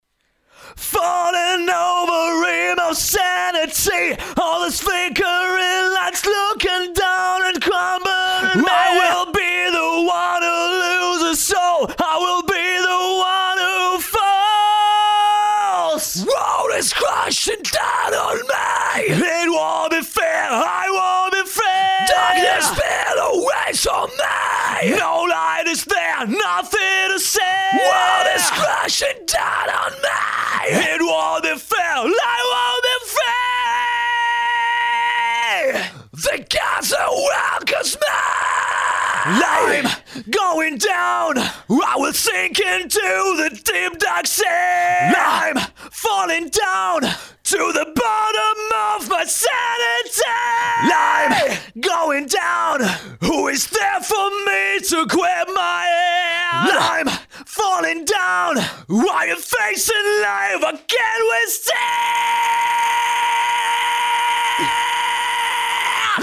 Вокал. Демо